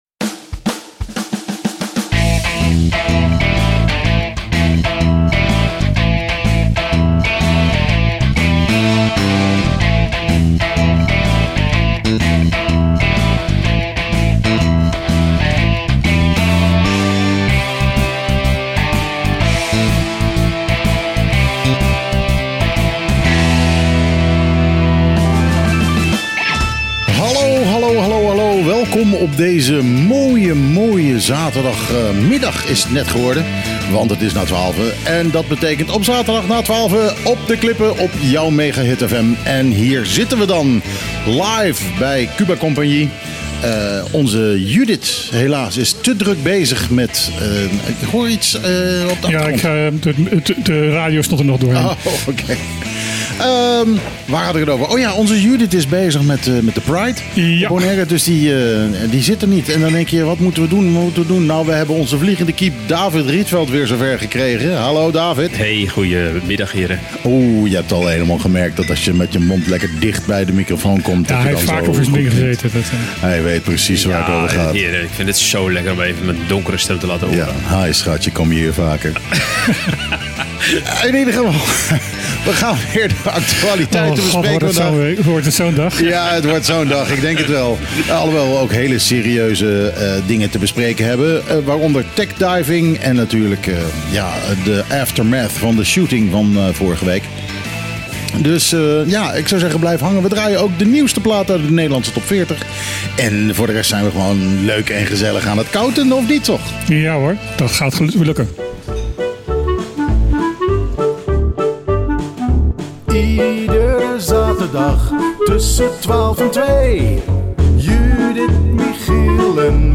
De integrale opname van de uitzending van het radioprogramma Op de Klippen. Uitgezonden op 21 juni 2025 vanaf het terras van Cuba Compagnie , Bonaire door het radiostation Mega Hit Fm (101.1 Mhz) Gasten